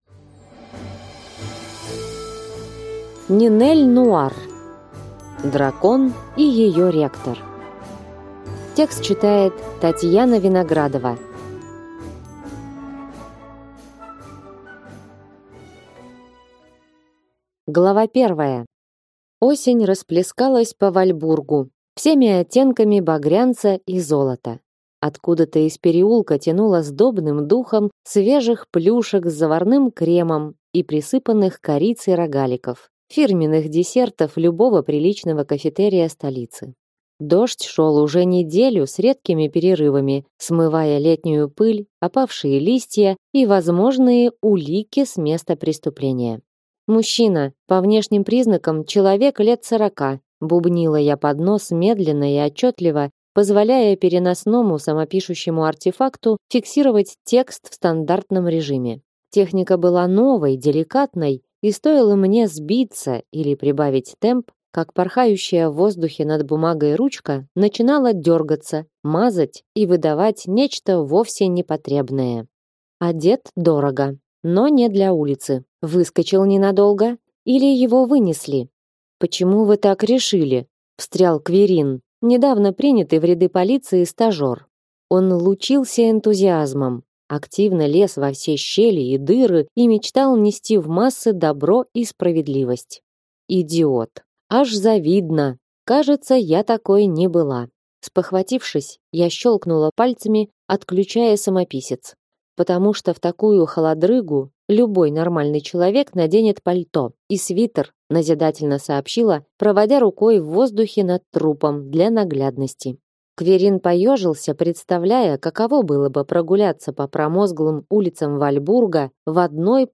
Аудиокнига Дракон… и ее ректор | Библиотека аудиокниг